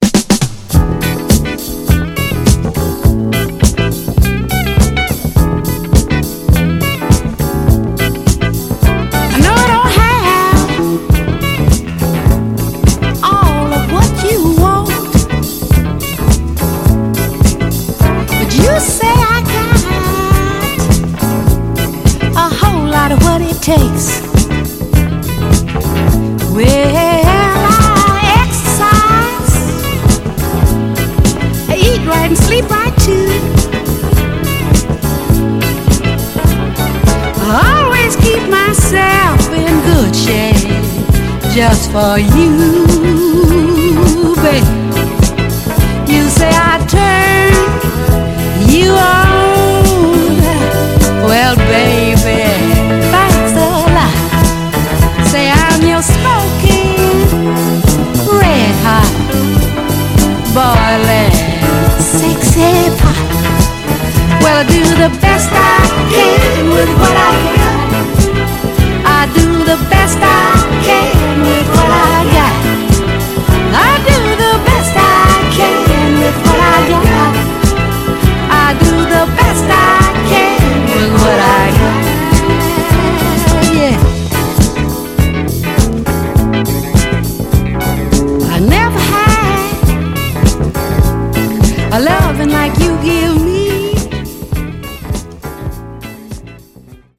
程よいピッチのモダン・ソウル・ダンサーとしても、使い勝手の良い一枚です。
※試聴音源は実際にお送りする商品から録音したものです※